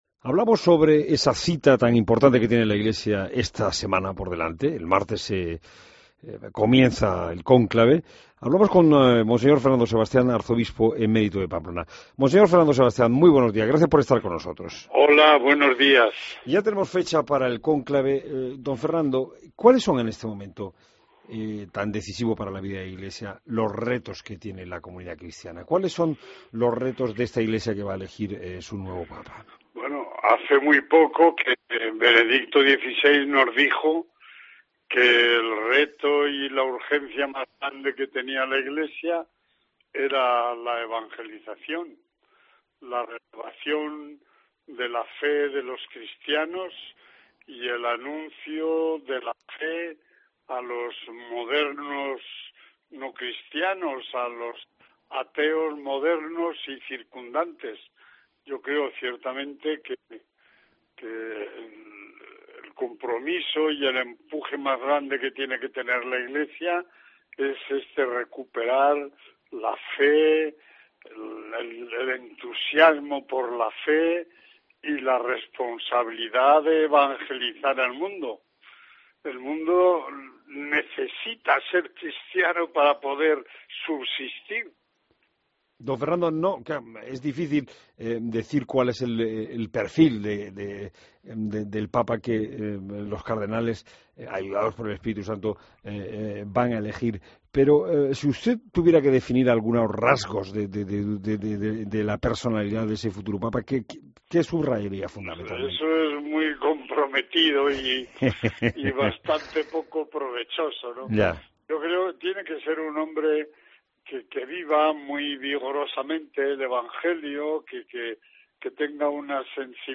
Escucha la entrevista a Monseñor Fernando Sebastián